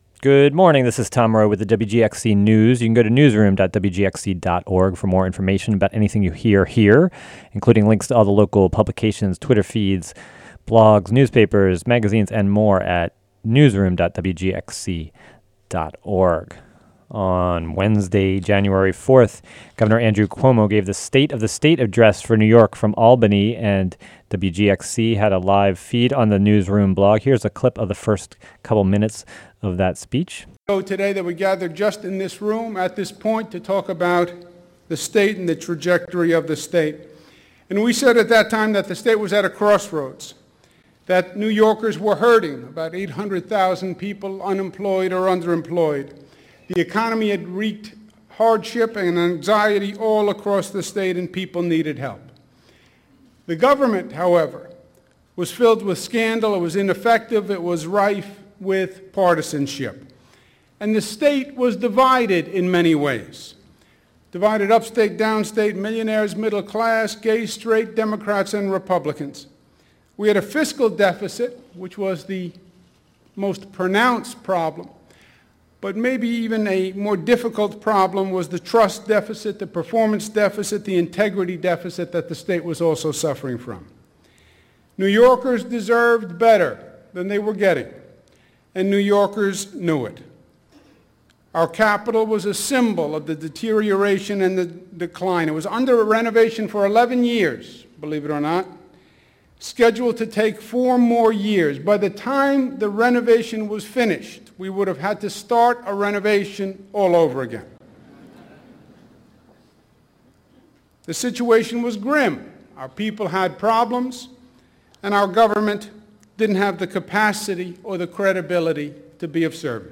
Includes recordings of protesters outside "State of the State" Address in Albany